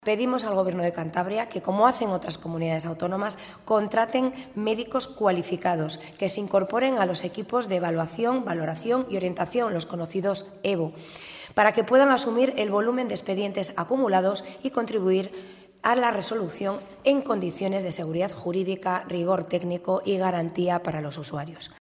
Ver declaraciones de Rosa Díaz, diputada del Partido Regionalista de Cantabria y portavoz del PRC en materia de Políticas Sociales.
Rosa Díaz en la rueda de prensa que ha ofrecido hoy